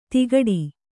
♪ tigaḍi